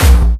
VEC3 Bassdrums Dirty 05.wav